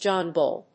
アクセントJóhn Búll